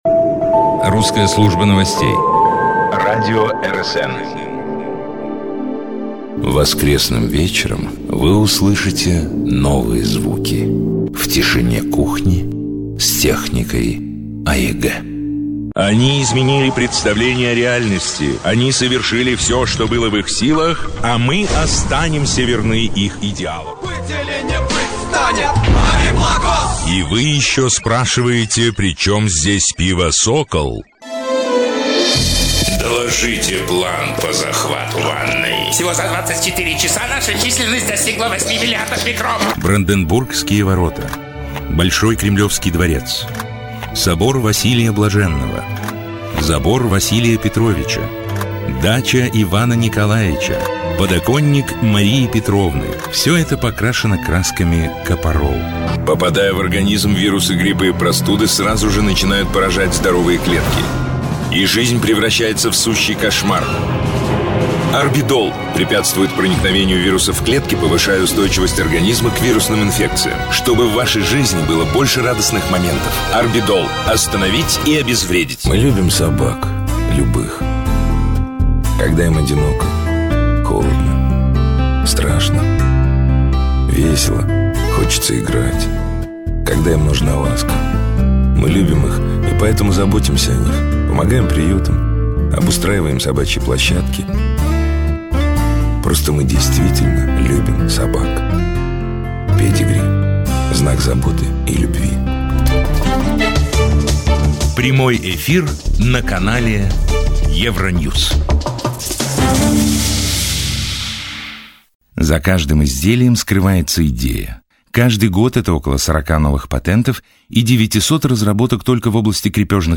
Ведущие международные и российские брэнды доверяют его голосу свою теле- и радиорекламу. Фантастический диапазон возможностей голоса - одинаково органичен во всем спектре - от величаво-патриотического и нежно-эротического, до забавно-мультяшного.
male-demo-vengerov.mp3